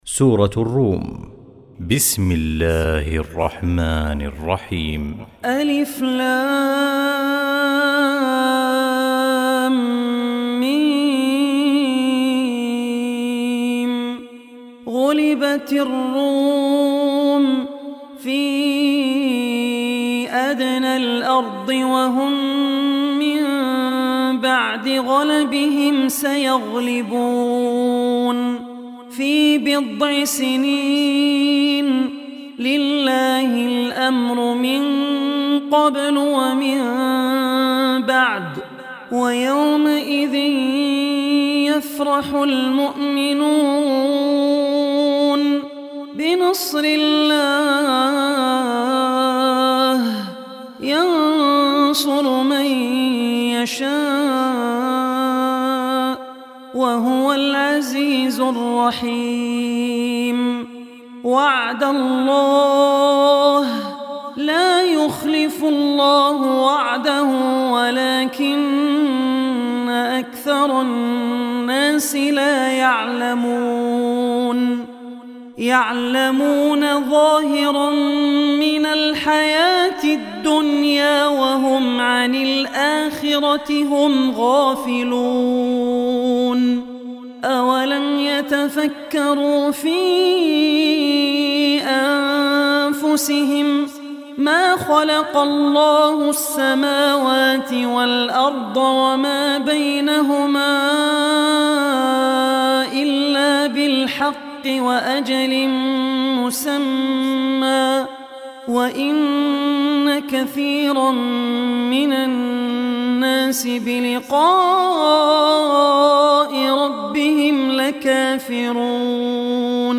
Surah Ar-Rum Recitation